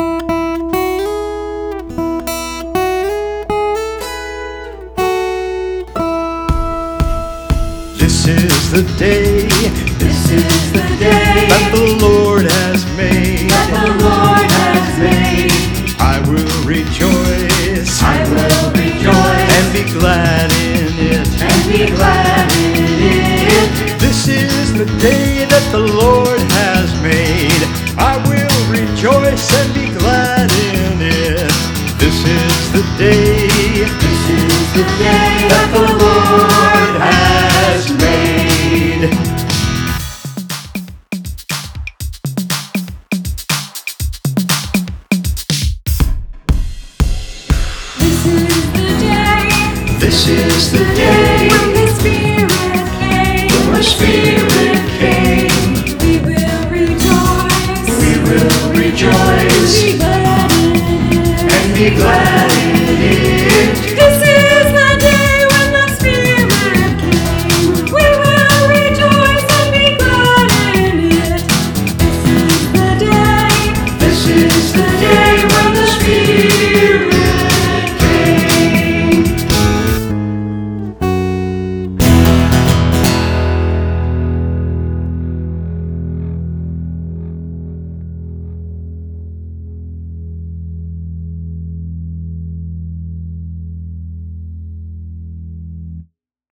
Click to sing & play along with a recording.